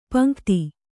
♪ paŋkti